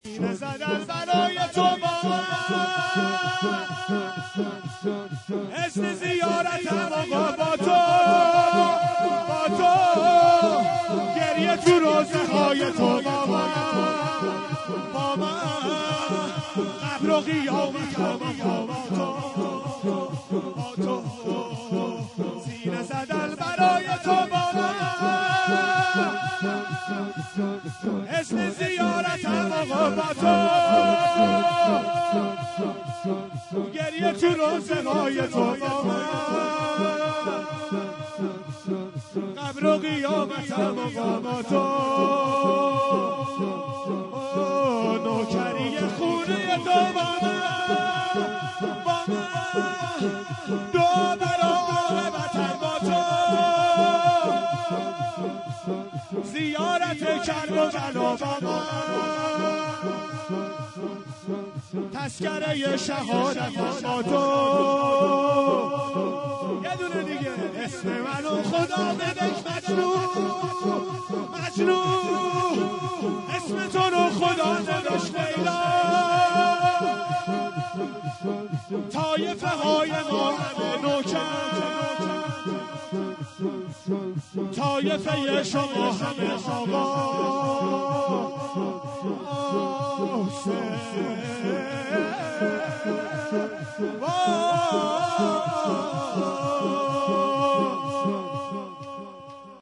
در ادامه صوت امداحی این مراسم منتشر می شود.
شور سینه زدن برای تو با من)